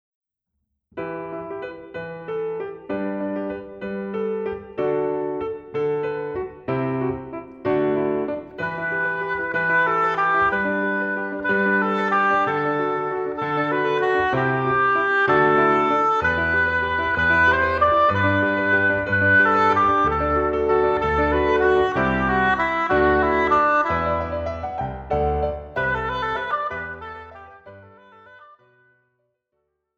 Besetzung: Oboe und Klavier